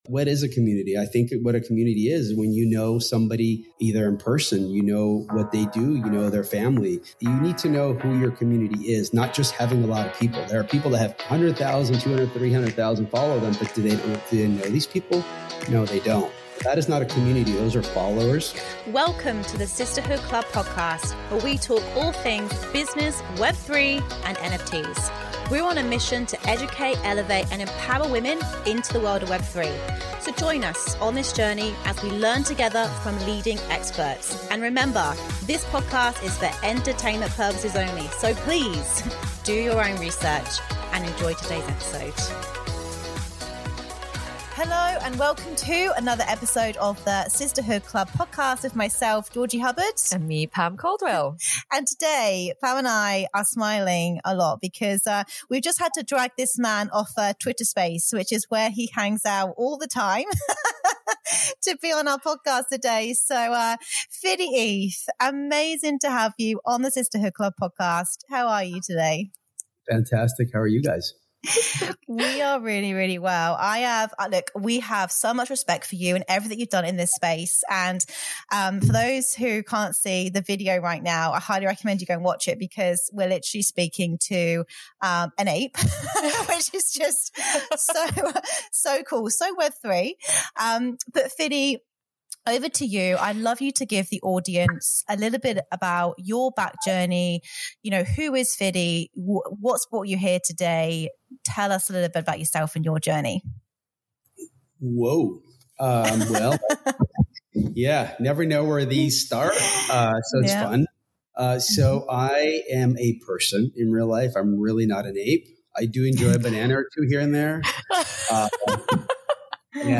We smiled throughout this entire conversation!